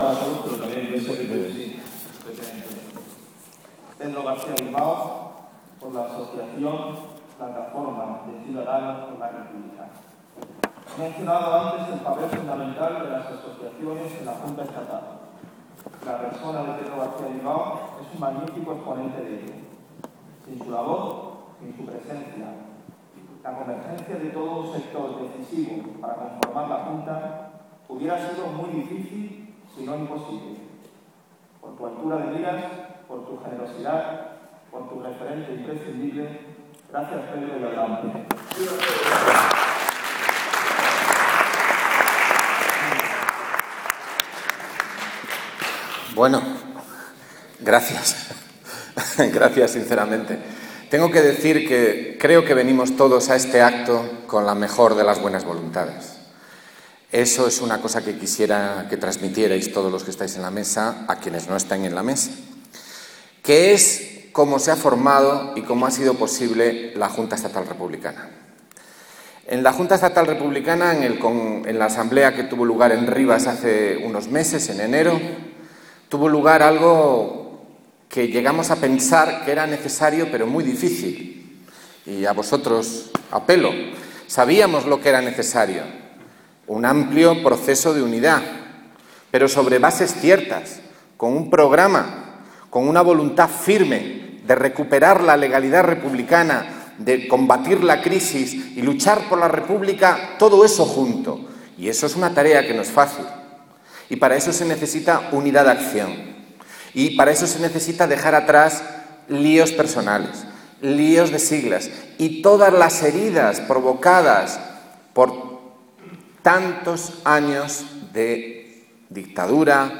Acto de la Junta Estatal Republicana, Madrid, 13 de abril de 2013 / Intervención